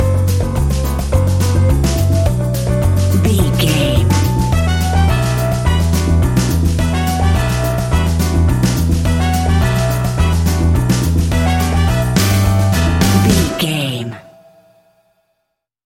An exotic and colorful piece of Espanic and Latin music.
Uplifting
Aeolian/Minor
flamenco
romantic
maracas
percussion spanish guitar
latin guitar